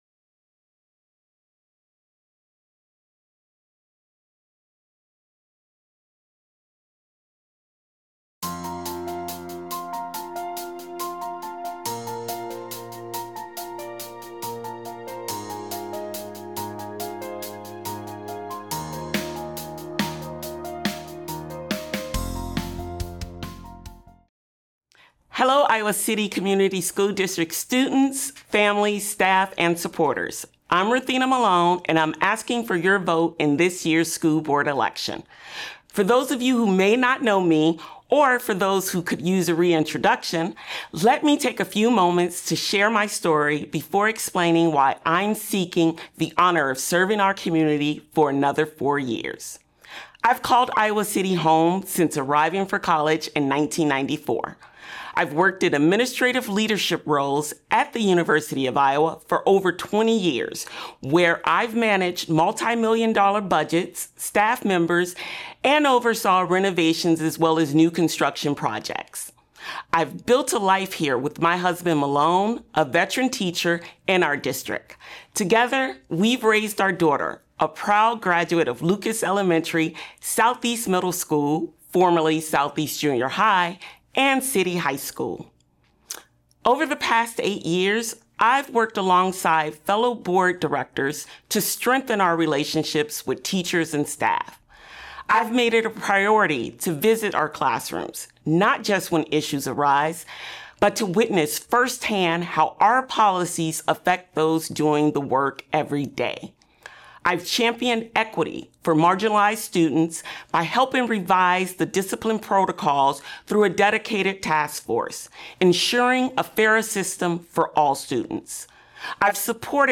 City Channel 4's Meet the Candidates project invited all of the registered candidates for the November 4th Iowa City Community School District Board of Directors election to come to our studio to present themselves to voters in five minutes or less.